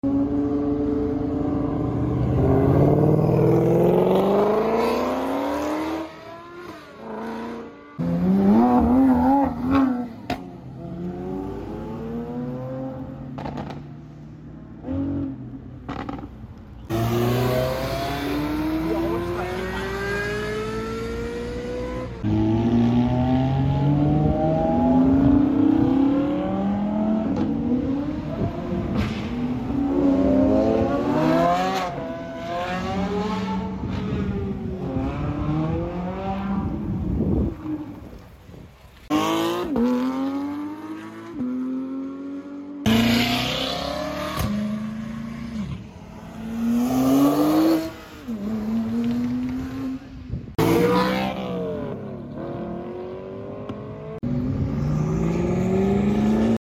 Supercars leaving cars and coffee sound effects free download